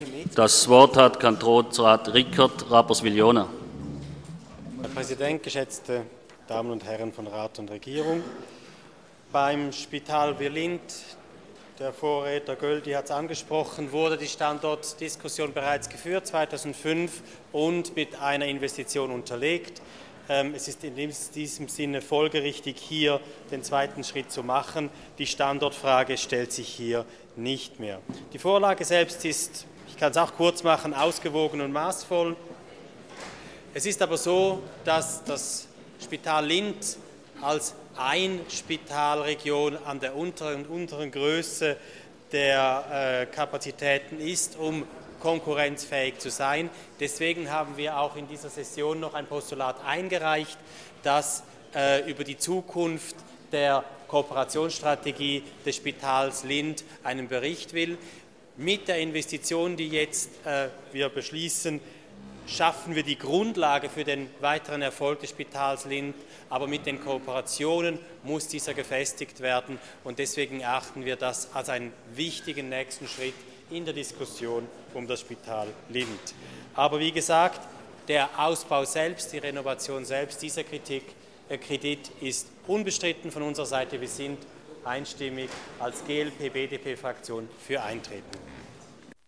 Session des Kantonsrates vom 26. Februar 2014, ausserordentliche Session